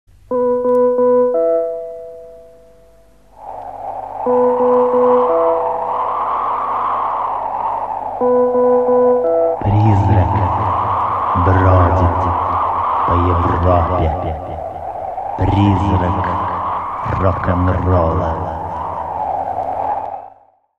МузЗаставки